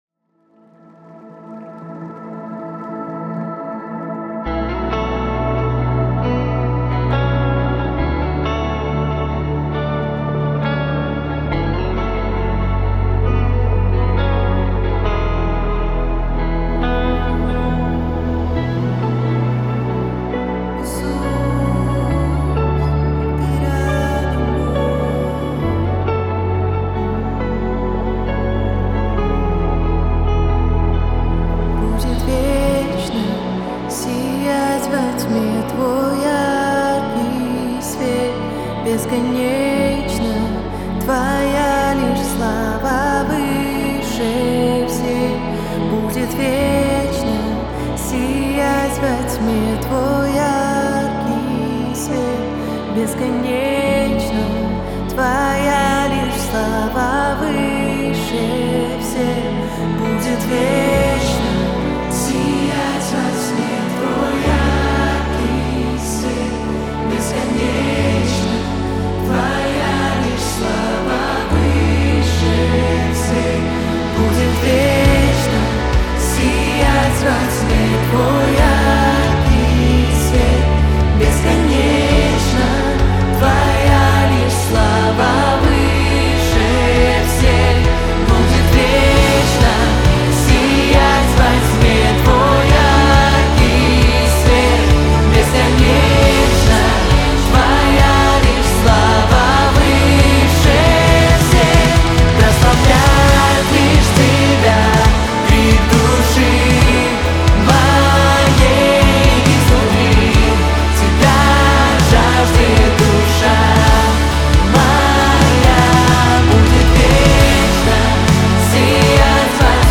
138 просмотров 744 прослушивания 30 скачиваний BPM: 68